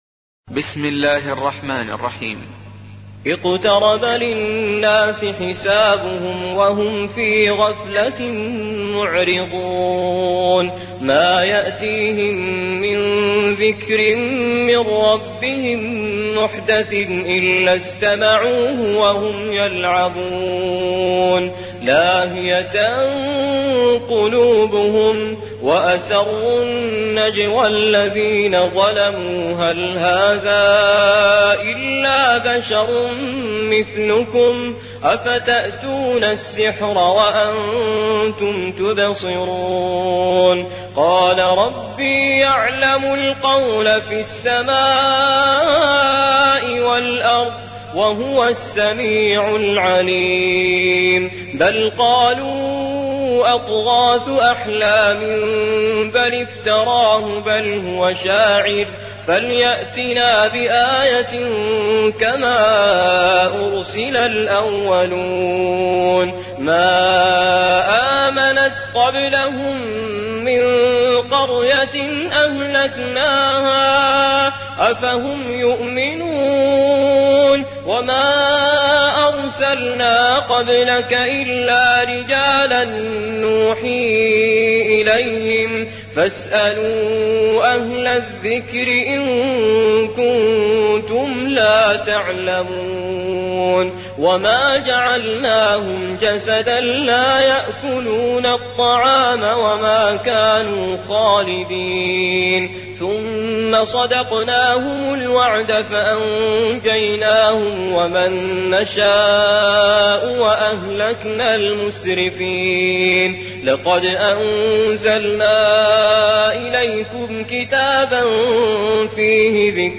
21. سورة الأنبياء / القارئ